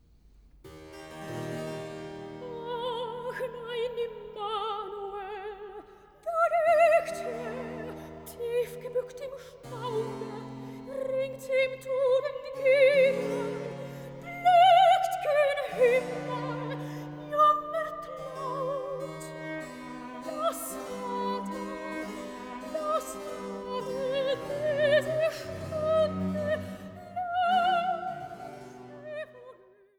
Passionskantate für Soli, Chor und Orchester
Choral „Wen hab‘ ich sonst, als dich allein“